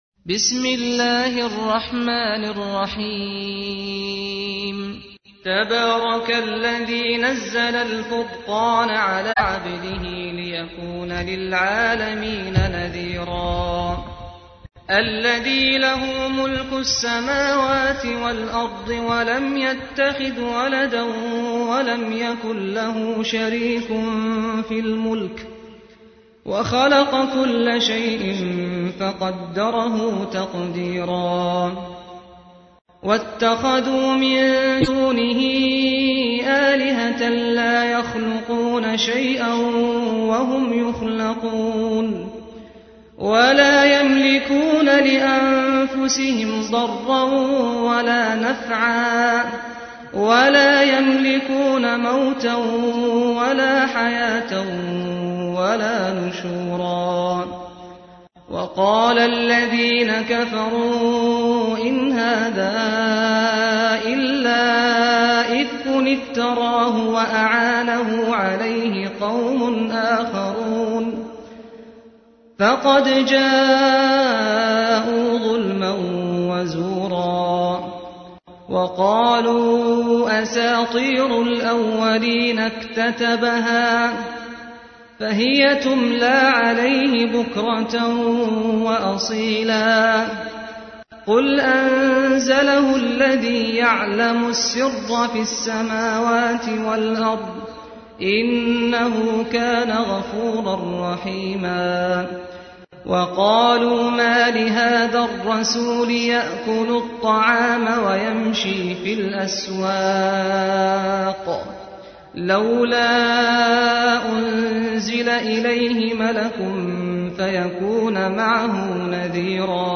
تحميل : 25. سورة الفرقان / القارئ سعد الغامدي / القرآن الكريم / موقع يا حسين